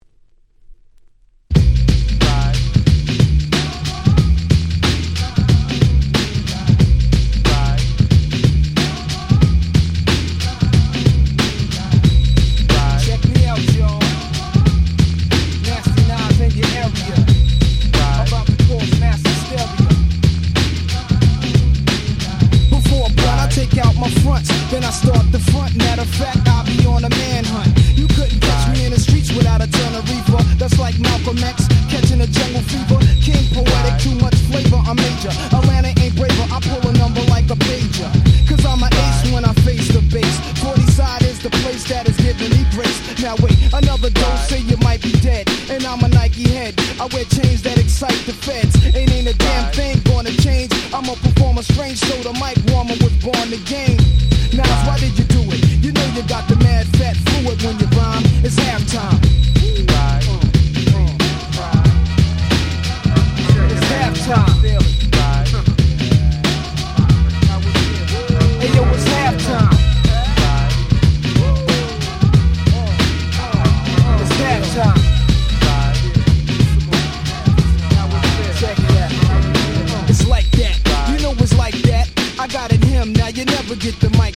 99' Super Hit Hip Hop !!
Boom Bap